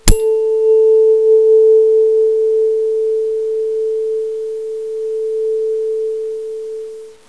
Stimmgabel 440 Hz
Abb. 01: Stimmgabel für 440 Hz
stimmgabel3-2-mono22.wav